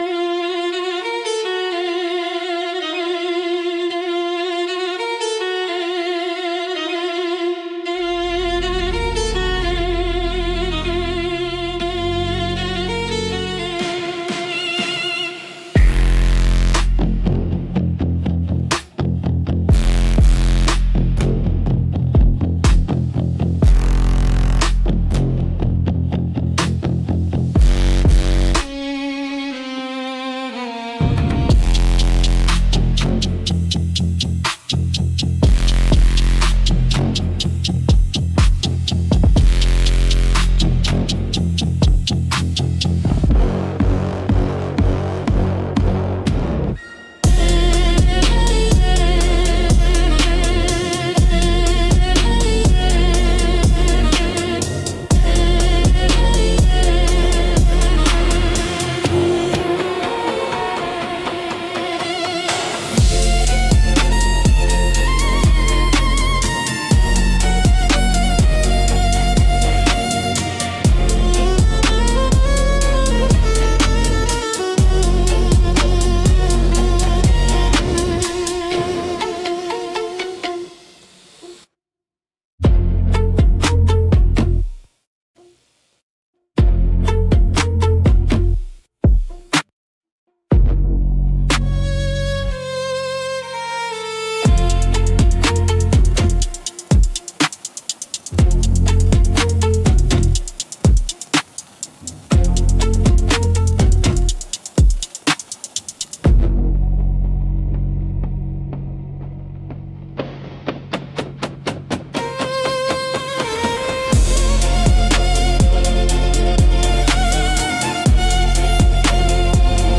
Instrumental - 808 Bass x Haunted Violin Trap